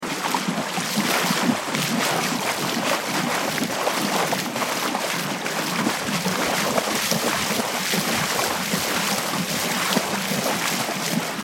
دانلود آهنگ دریا 9 از افکت صوتی طبیعت و محیط
جلوه های صوتی
دانلود صدای دریا 9 از ساعد نیوز با لینک مستقیم و کیفیت بالا